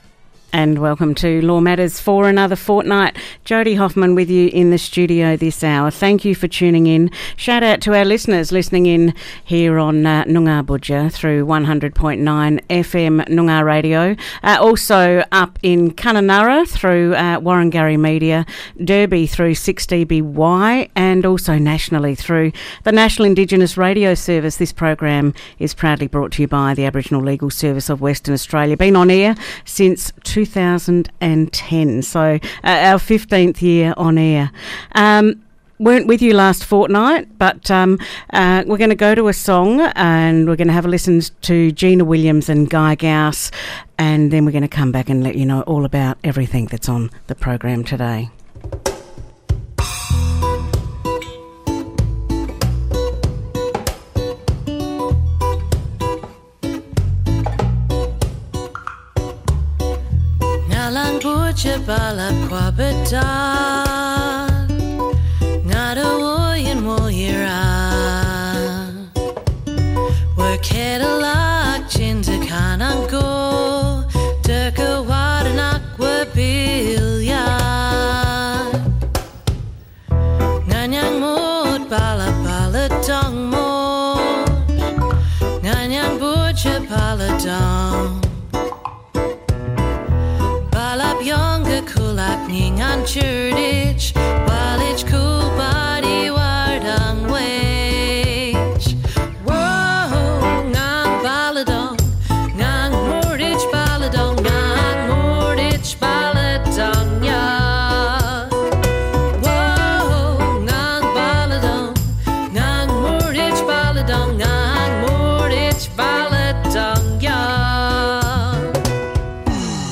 Guests: WA Governor the Honourable Chris Dawson.